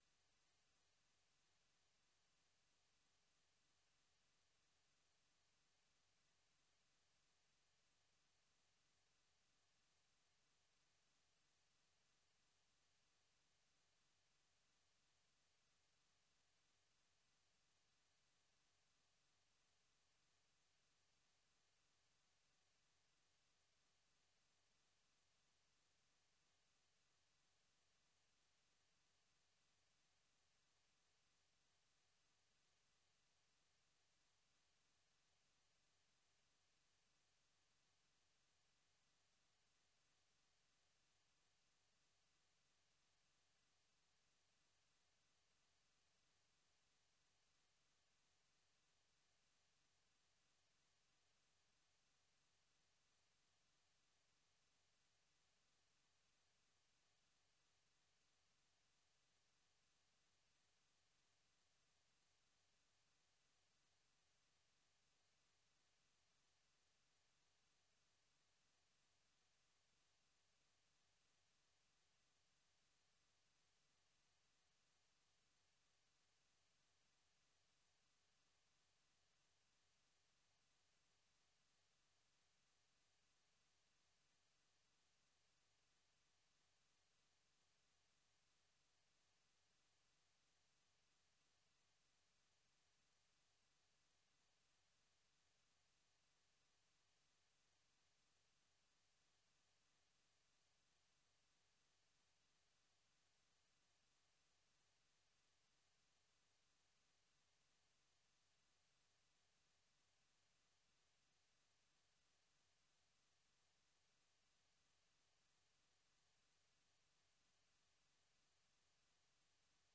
Idaacadda Fiidnimo ee Evening Edition waxaad ku maqashaan wararkii ugu danbeeyey ee Soomaaliya iyo Caalamka, barnaamijyo iyo wareysiyo ka turjumaya dhacdooyinka waqtigaasi ka dhacaya daafaha Dunida.